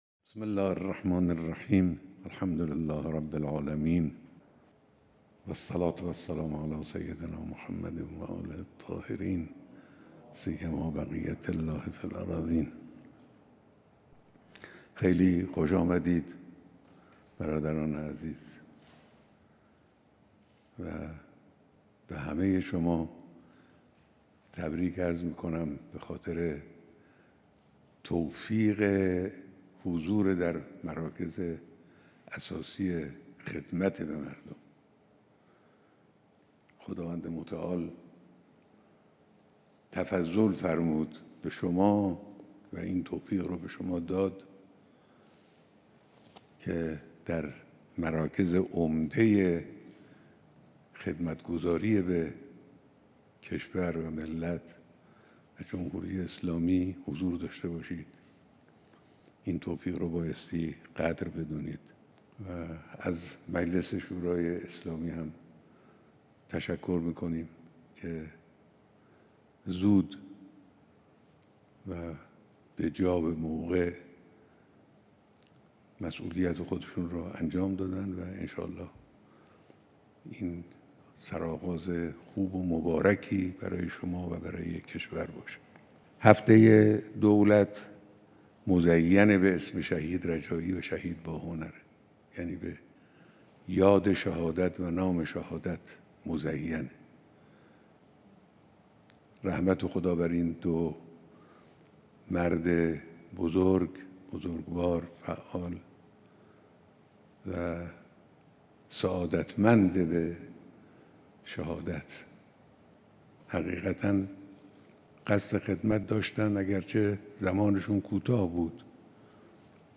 بیانات در دیدار رئیس‌جمهور و اعضاء هیئت دولت سیزدهم